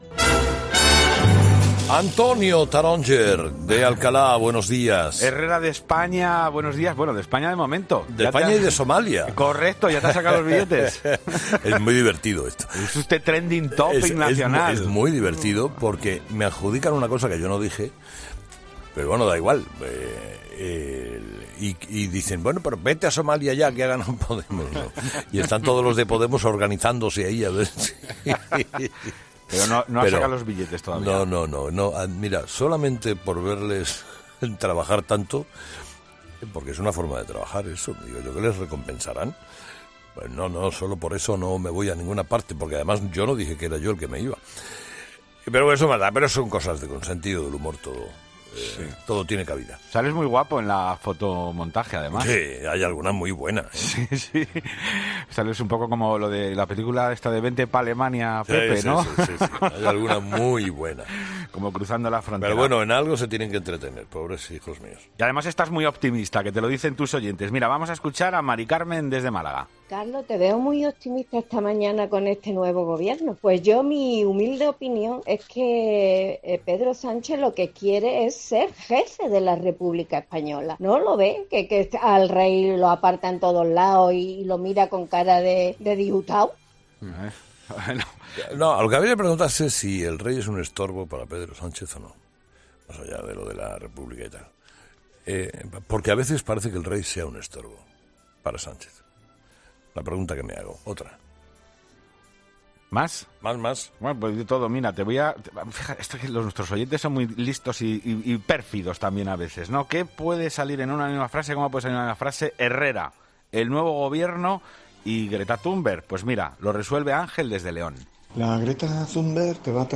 Avalancha de mensajes en el contestador de 'Herrera en COPE' con los temas del día.